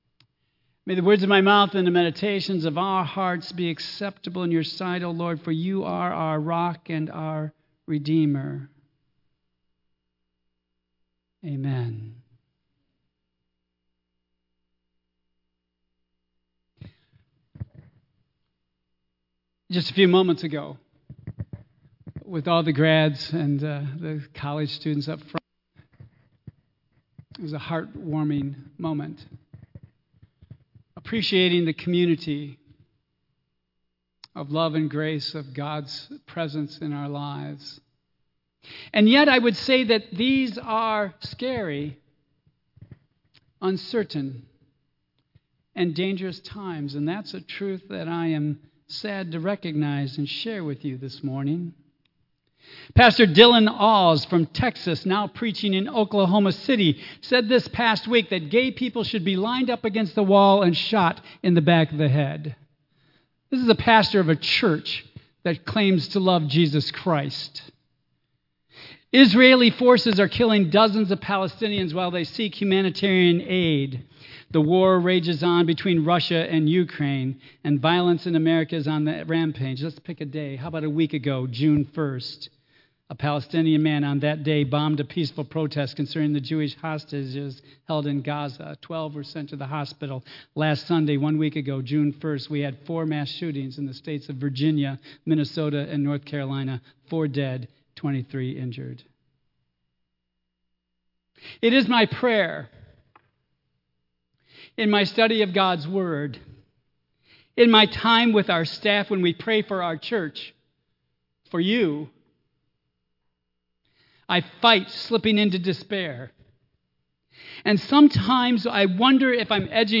Tagged with Central United Methodist Church , Michigan , Sermon , Waterford , Worship